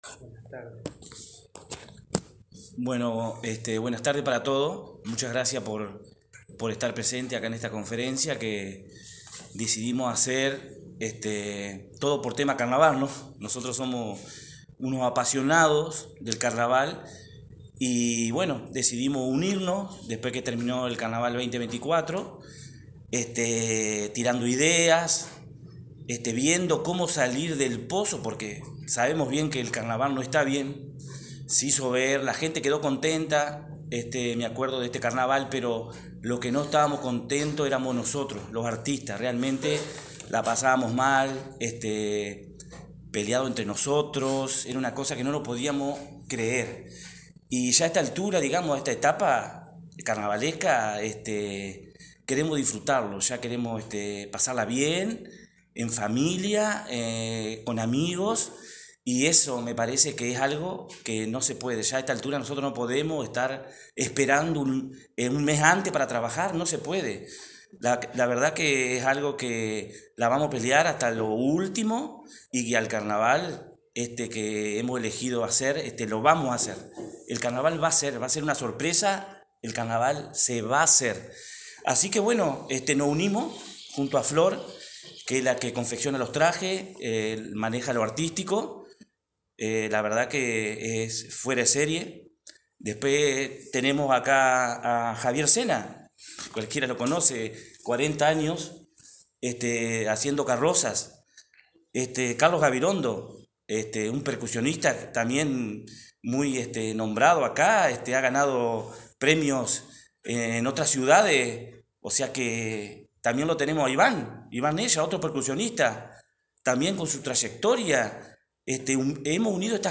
Ayer se realizó una conferencia de prensa por parte de los organizadores, en un bar de Mastrángelo y Perón, que impulsan un carnaval alternativo.